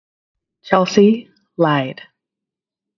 Hear name pronounced.